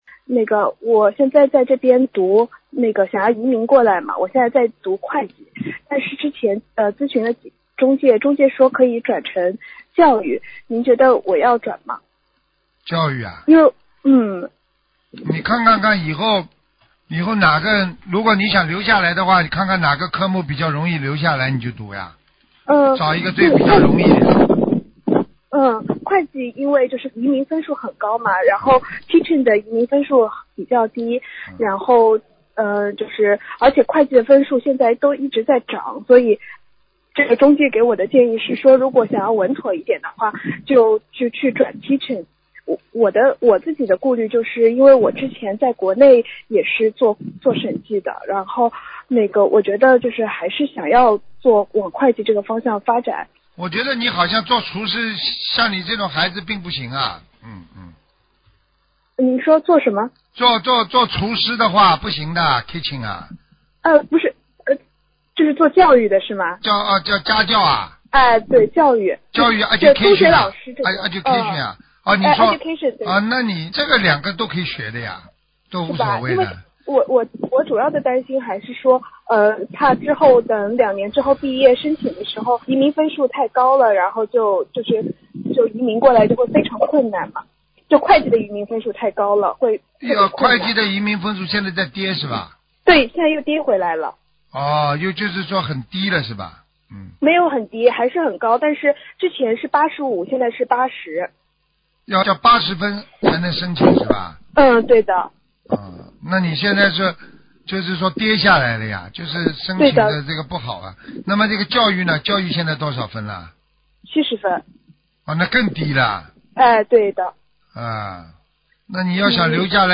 但因对话交流带有语气，文字整理不可能完全还原情境。